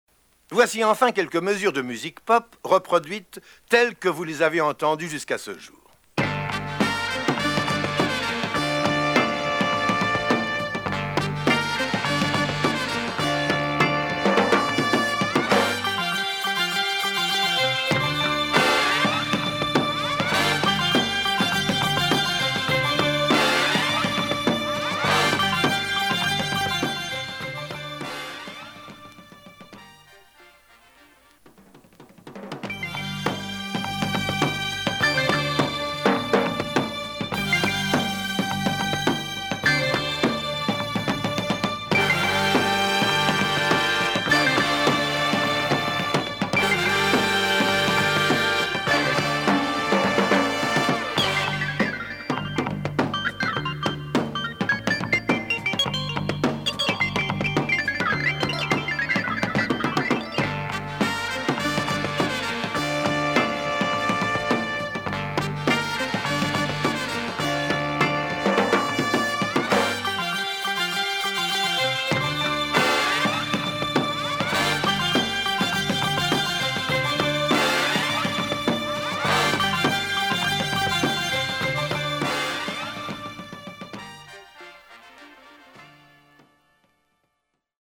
Obscure French record released in the early 70’s